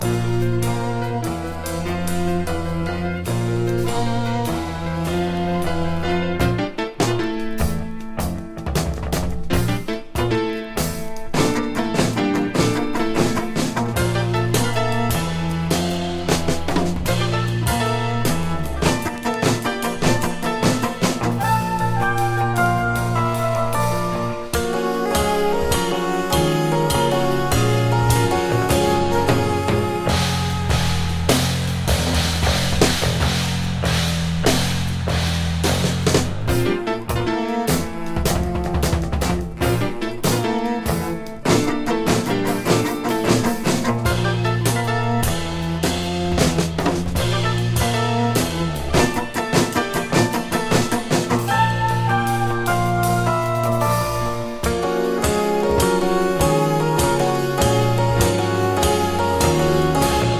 ベルギー・チェンバーロックグループ ’84年作。